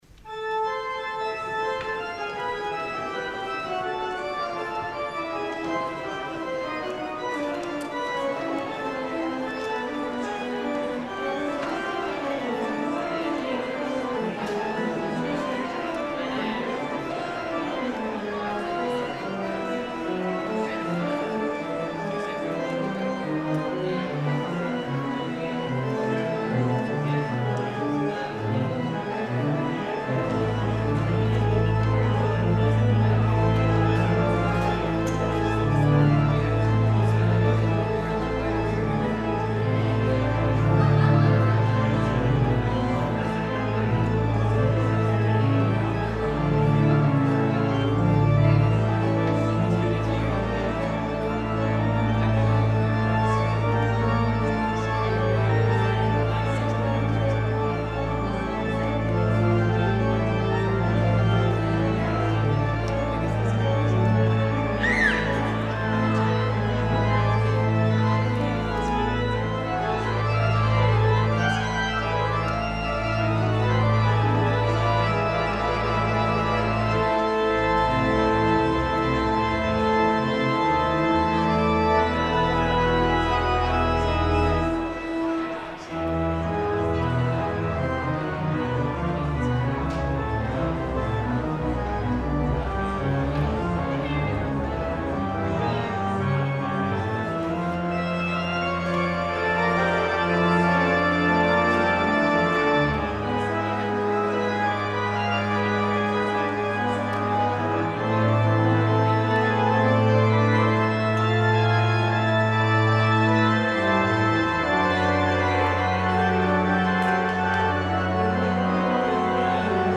†POSTLUDE
organ